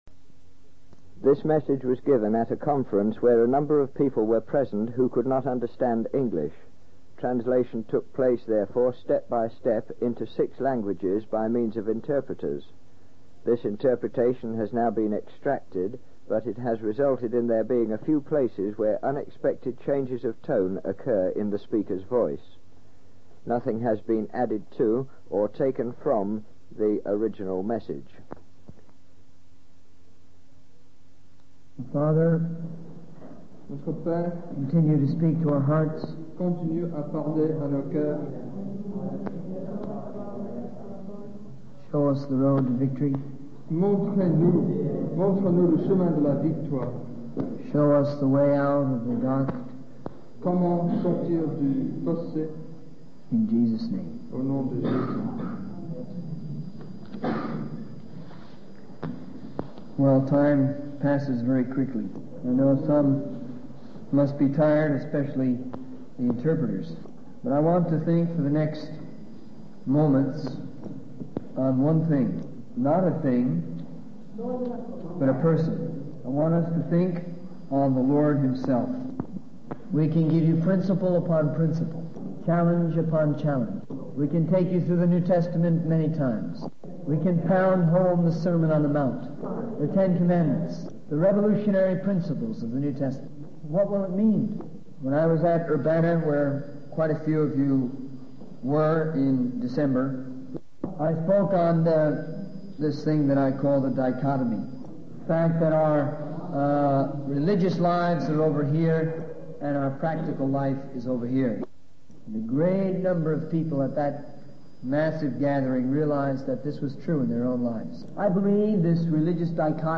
In this sermon, the speaker encourages the audience to focus on Jesus and not get caught up in various principles, methods, or projects.
Full Transcript This message was given at a conference where a number of people were present who could not understand English.
This interpretation has now been extracted, but it has resulted in there being a few places where unexpected changes of tone occur in the speaker's voice.